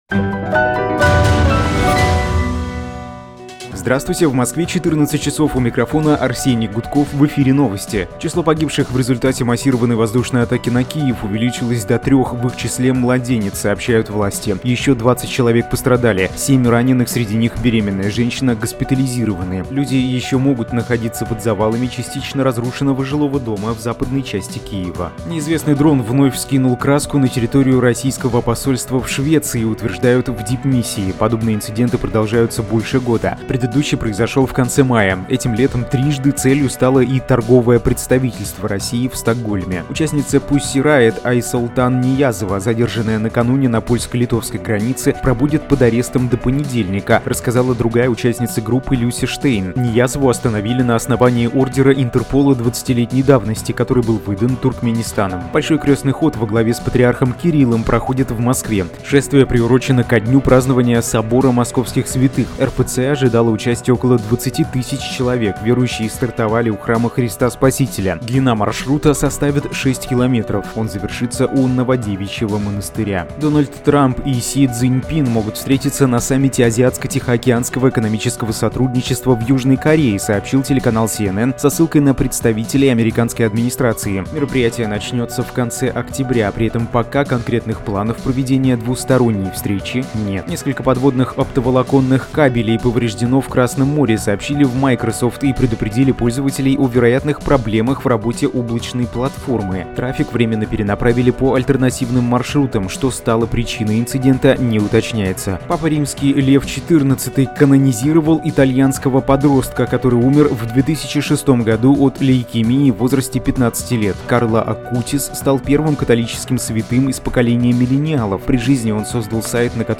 Слушайте свежий выпуск новостей «Эха»
Новости 14:00